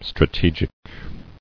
[stra·te·gic]